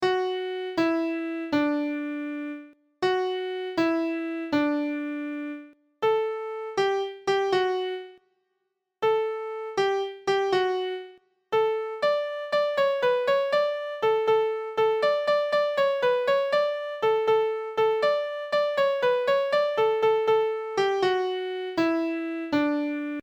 The canon, or rule, of a simple round is that each voice enters after a set interval of time, at the same pitch, using the same notes.[10]
Three_Blind_Mice.ogg.mp3